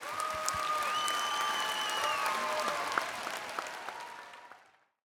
sfx_crowd_cheer.ogg